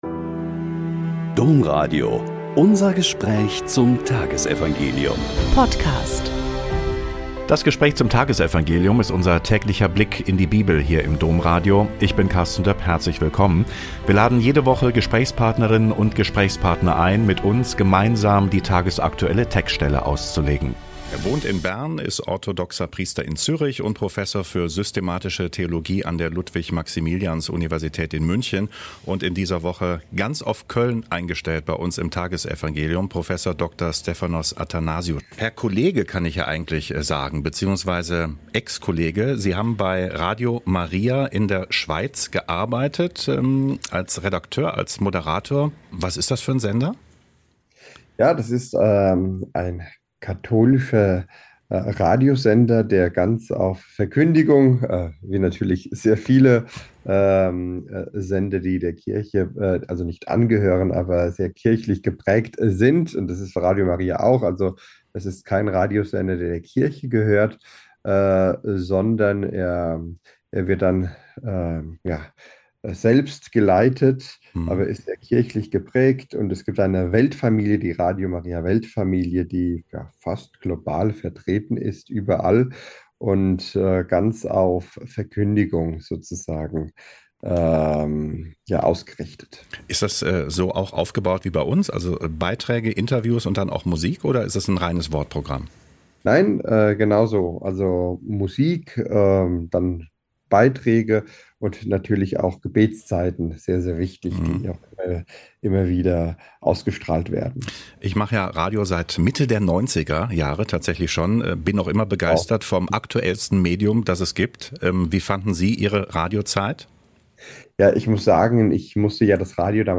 Mt 20,1-16a - Gespräch